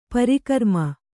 ♪ parikarma